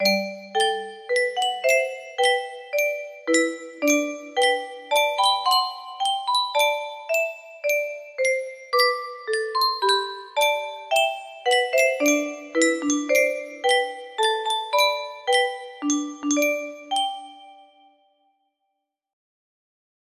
애국가 - 안익태 music box melody
Grand Illusions 30 (F scale)